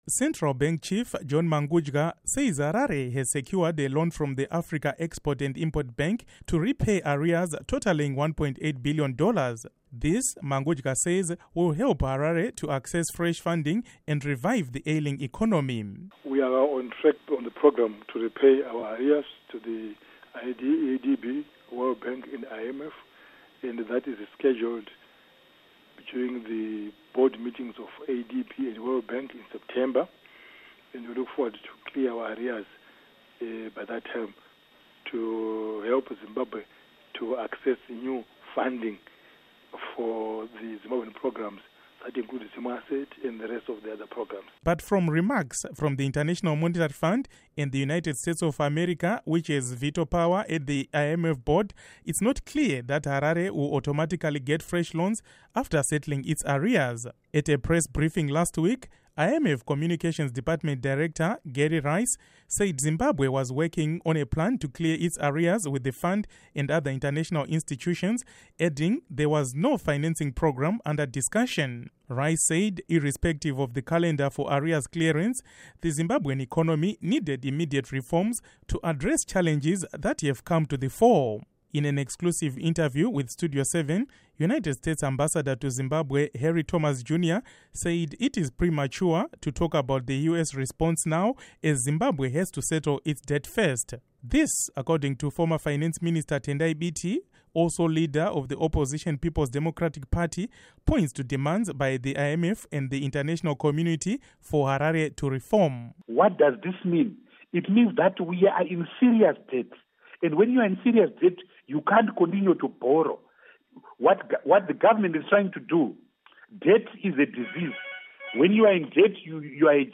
Report on Economy